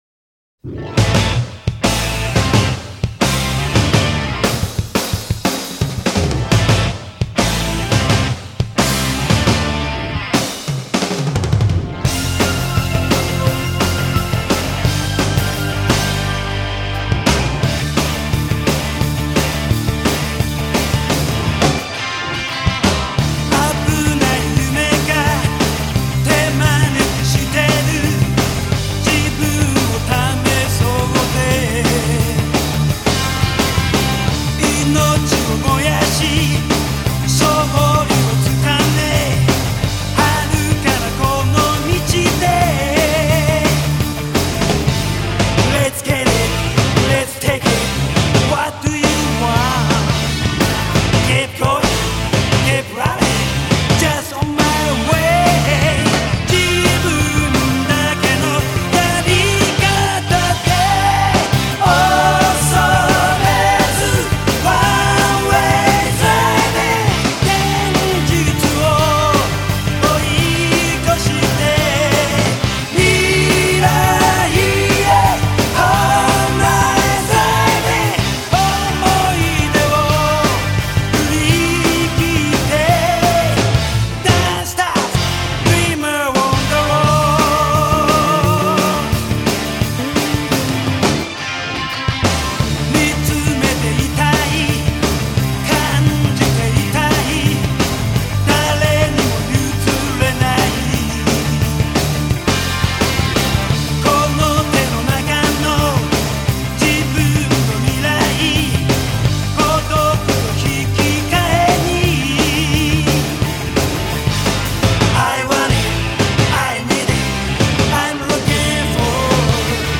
(오케스트라 사운드가 거의 사용되지 않았으며 기계적인 사운드에만 의존.)
노래는 괜찮은데 보컬이 너무 악을쓰네요... 안스러워라.....